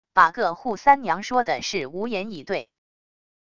把个扈三娘说的是无言以对wav音频生成系统WAV Audio Player